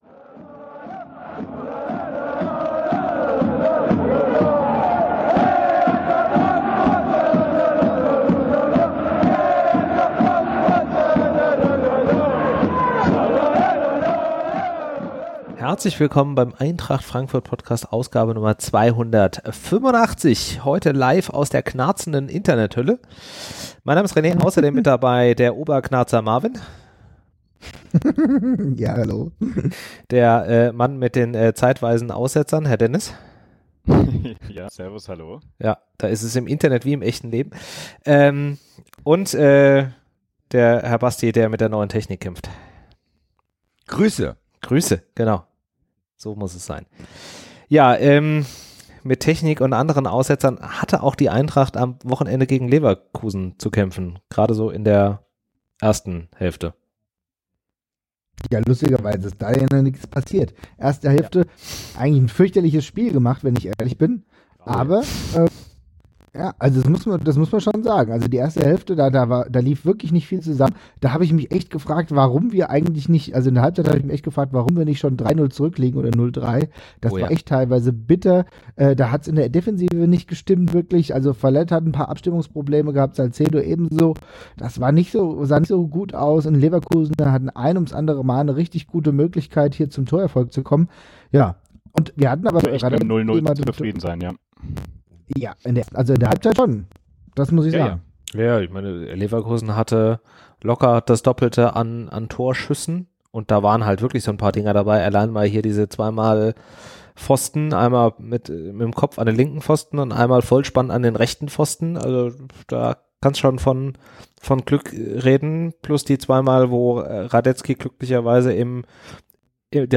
Nach anfänglichen Technischen Schwierigkeiten geht es richtig ab. Wir reden über Leverkusen, Berlin, die Eintracht, jede Menge Dummschwätzer inklusive einem Mega Lachflash und Empfehlungen.